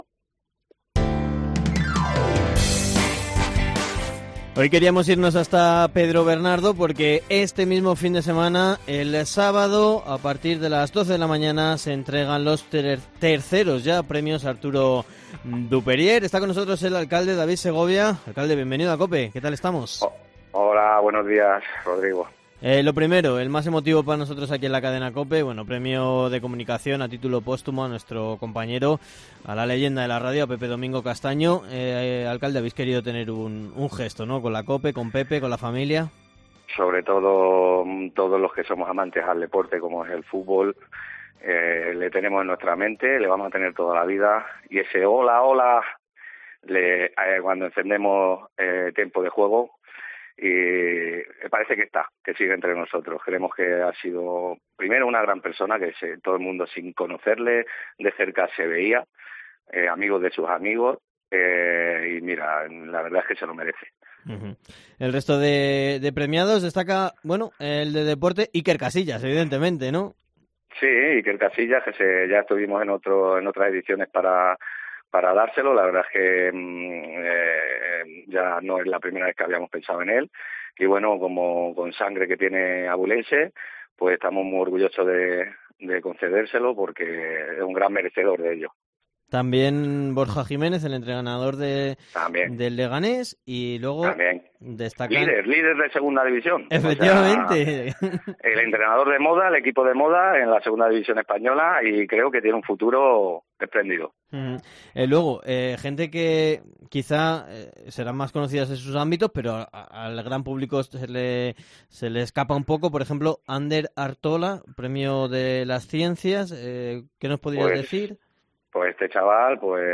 ENTREVISTA / El alcalde del Pedro Bernardo, David Segovia, en COPE